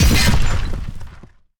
hit2.ogg